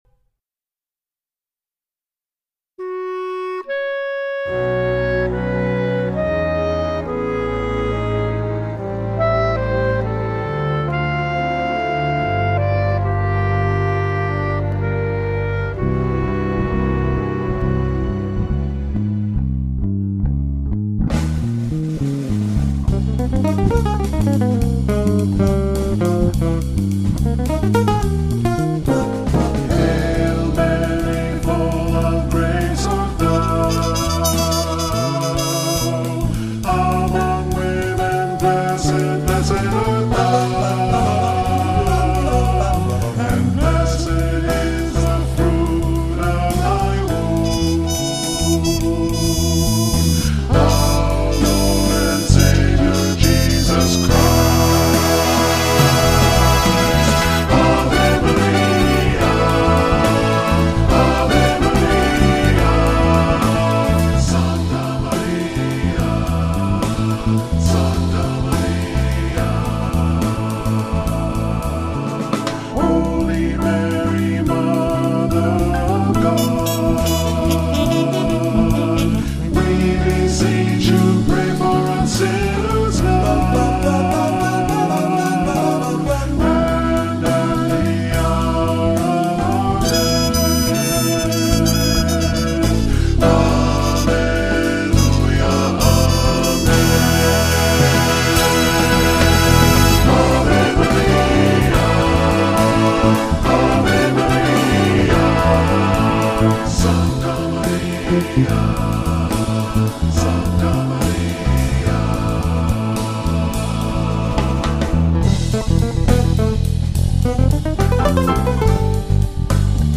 a Jazz Vespers